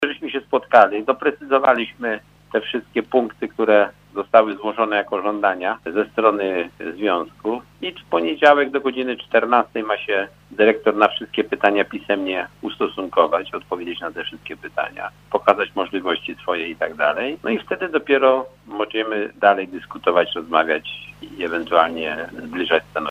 W czwartek dyrekcja szpitala spotkała się z pielęgniarkami w związku z postulatami jakie związek zawodowy Pielęgniarek i Położnych wystosował do dyrekcji lecznicy. Jeden z nich dotyczył podwyżki wynagrodzenia minimum o 1 800 złotych. Starosta stalowowolski Janusz Zarzeczny który uczestniczył w spotkaniu poinformował o temacie rozmów.